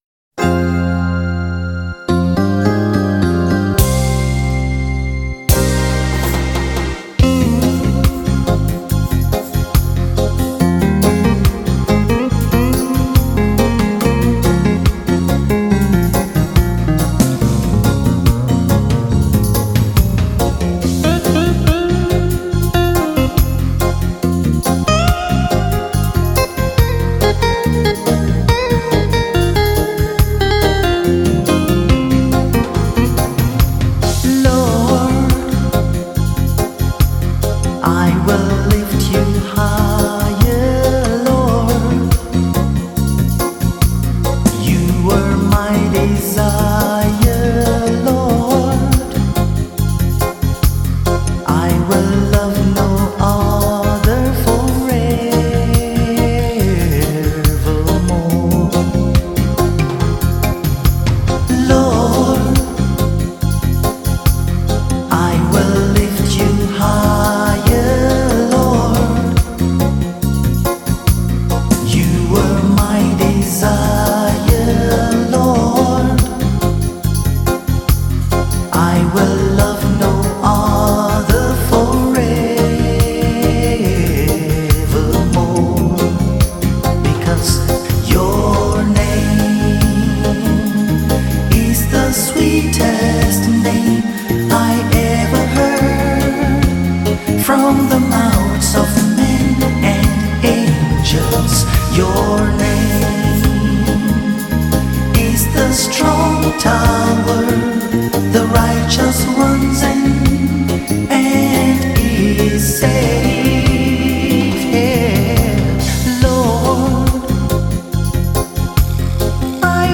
is a Nigerian gospel singer